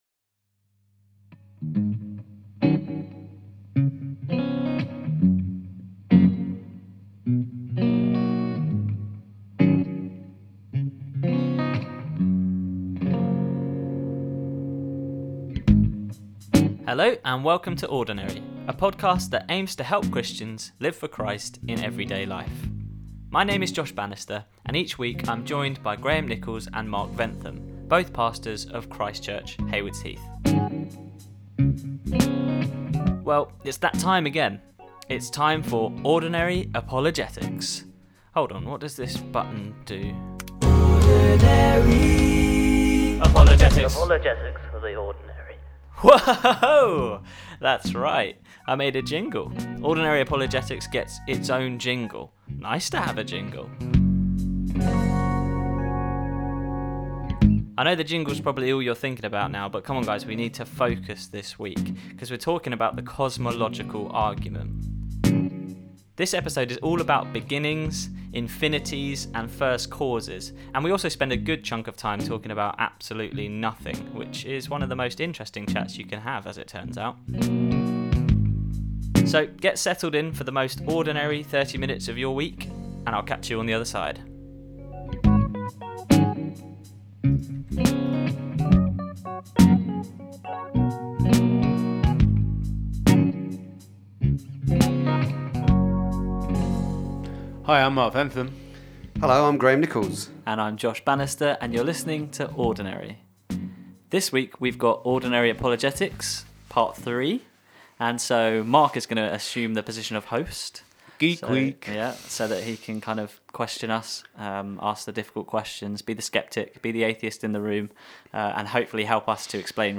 Also: Ordinary Apologetics now has a jingle!